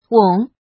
怎么读
wěng
weng3.mp3